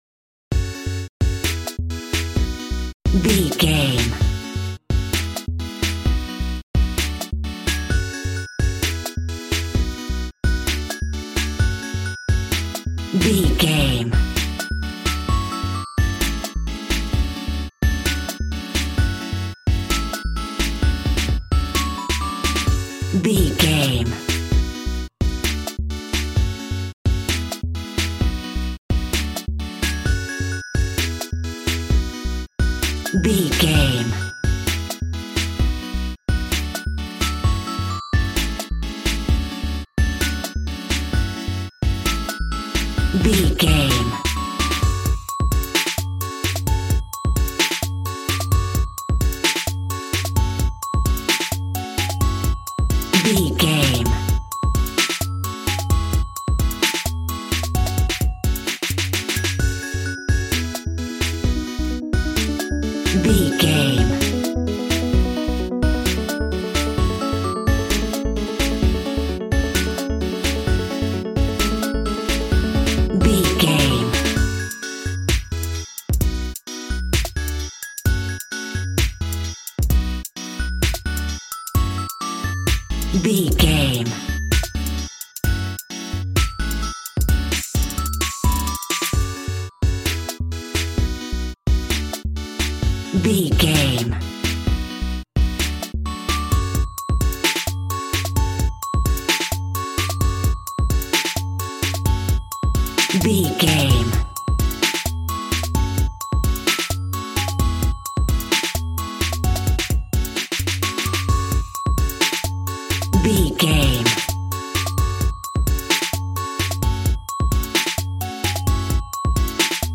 Aeolian/Minor
G♭
groovy
synthesiser
drums
piano